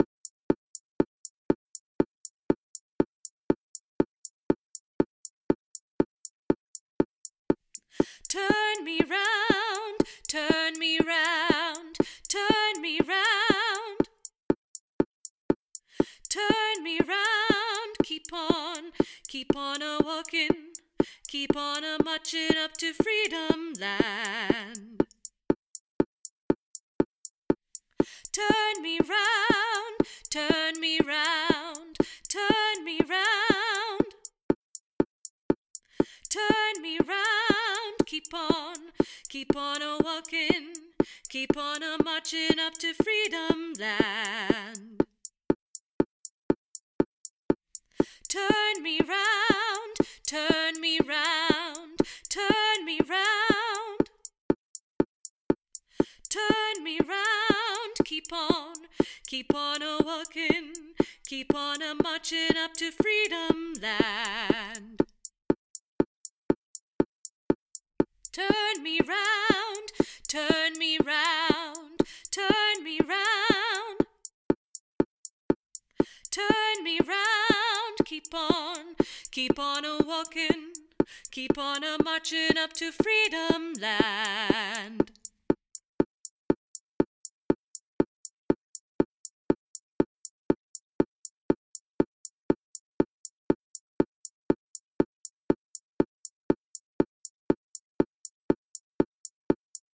AGLN Bass